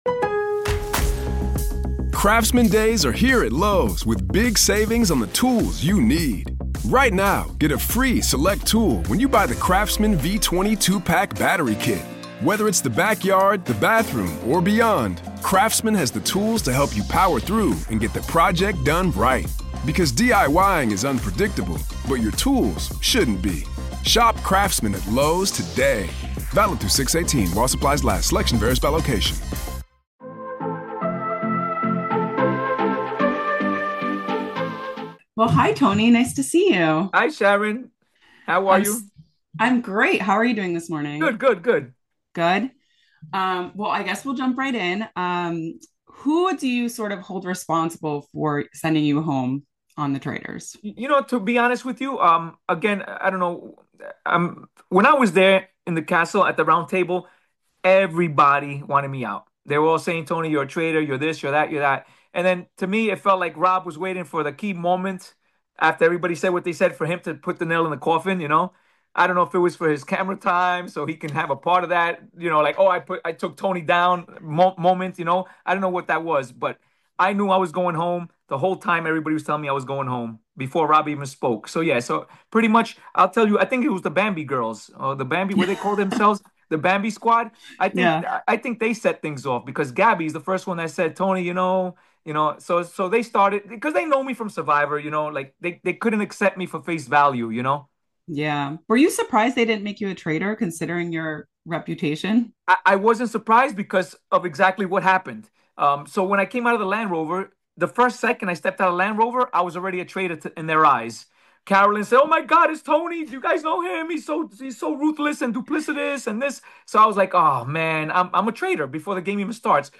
The Traitors Exit Interview: Tony Vlachos Calls Out Boston Rob's 'Stupid Move,' Reveals Where They Stand